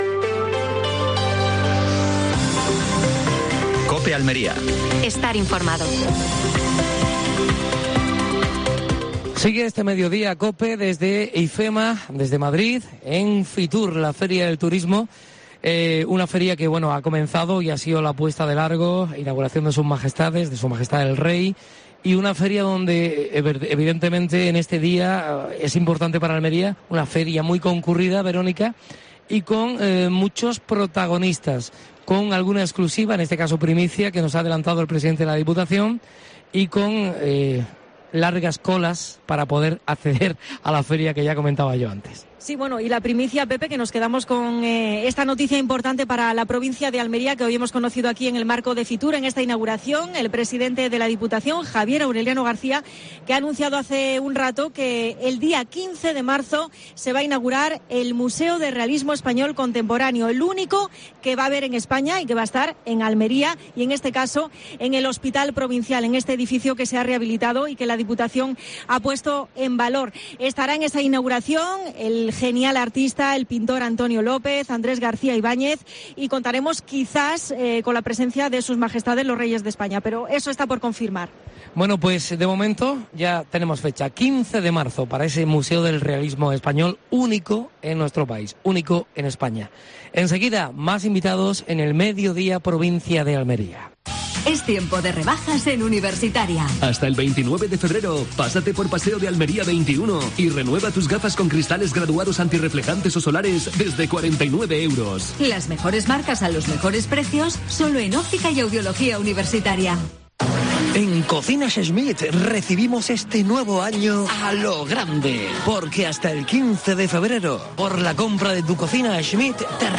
Última hora en Almería. Especial FITUR desde IFEMA (Madrid). Entrevista a Fernando Giménez (diputado provincial Turismo).